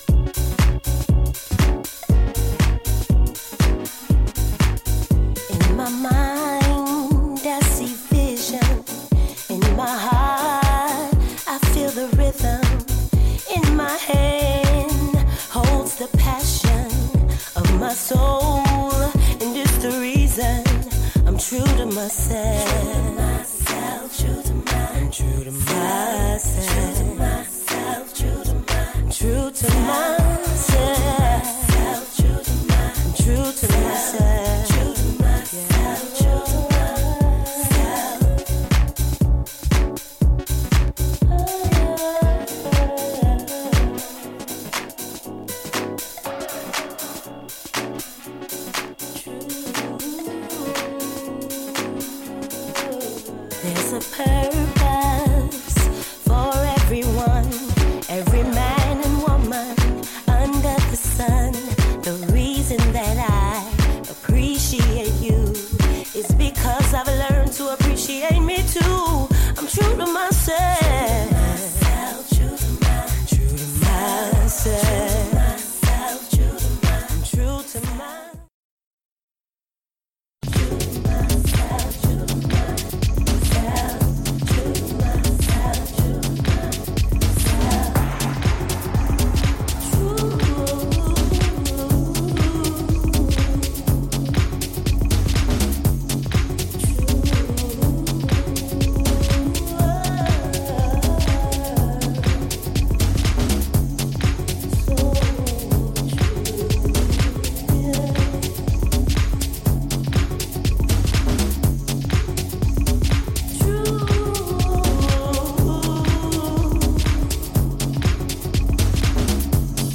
sublime soulful groover
warm deep house vibe teased with jazzy undercurrents
candid soul-drenched vocal